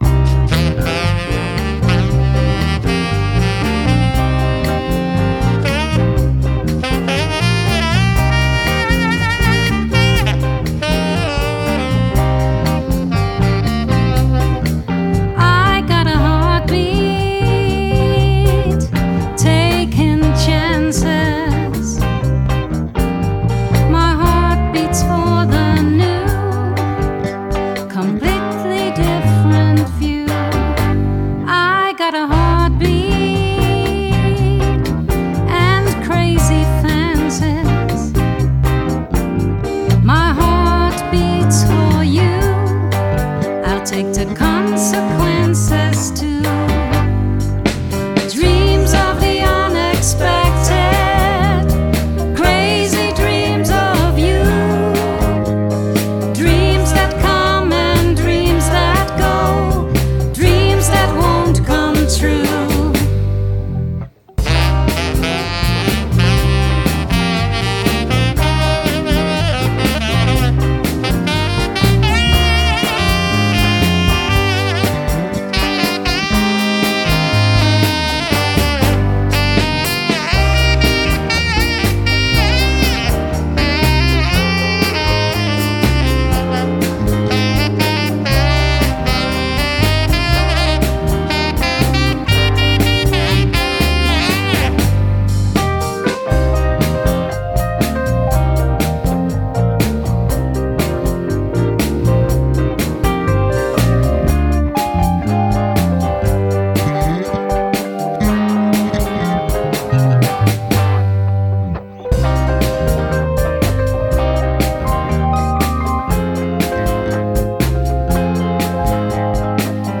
Studio-opnamen 2025